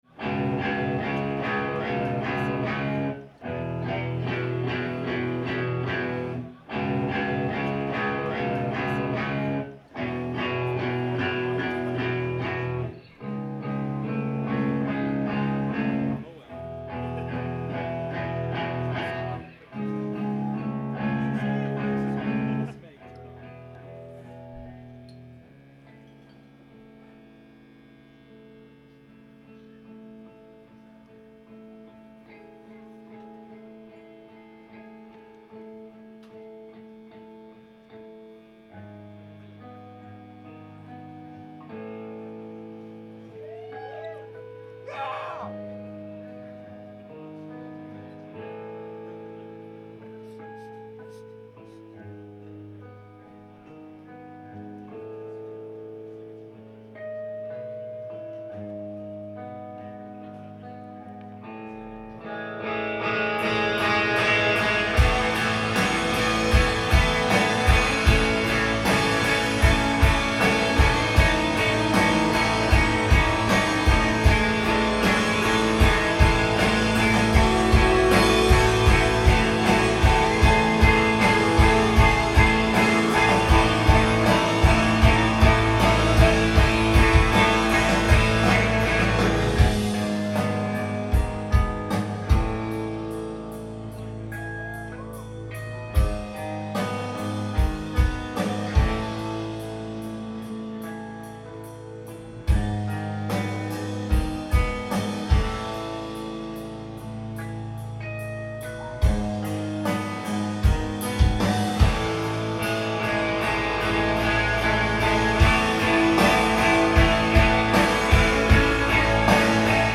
Live at Avalon
Boston, MA